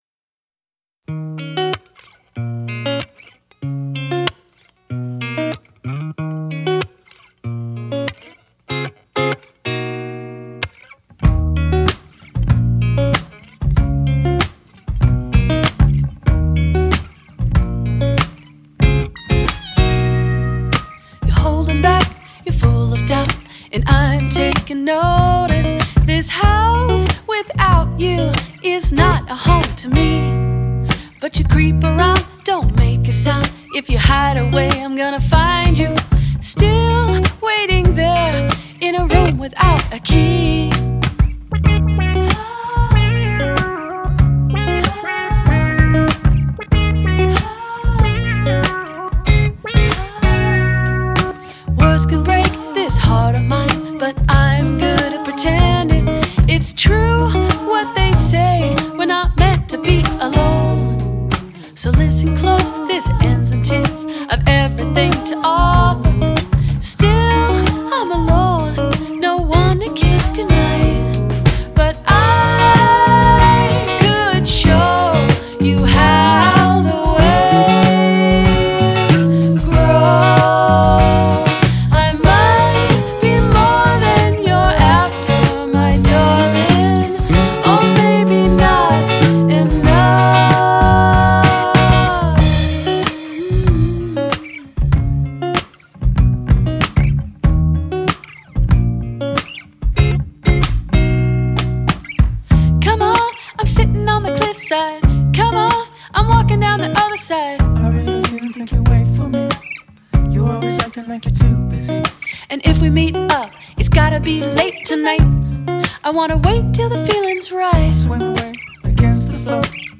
voce e tastiere